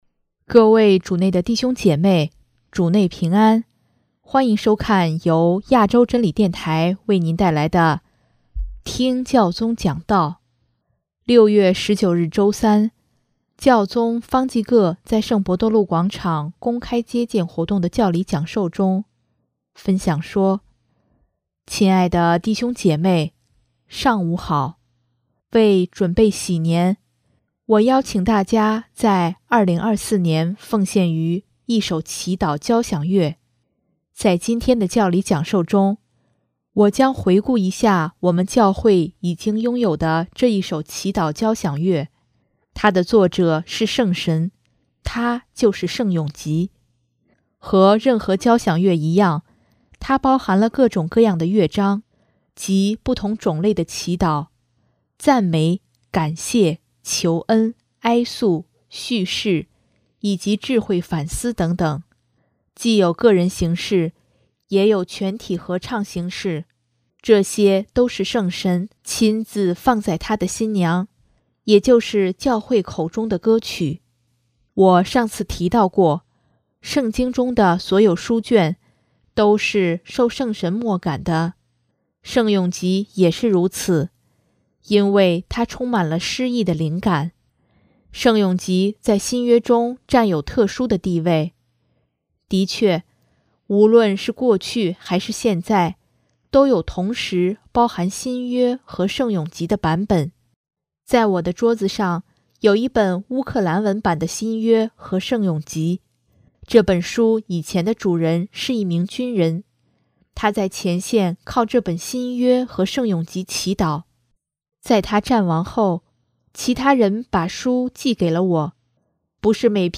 6月19日周三，教宗方济各在圣伯多禄广场公开接见活动的教理讲授中，分享说：